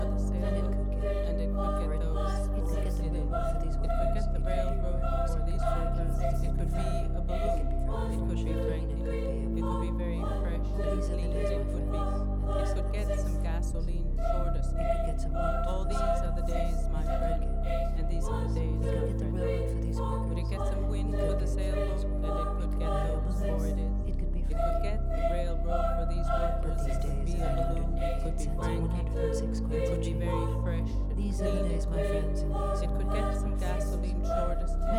Classical Minimalism
Жанр: Классика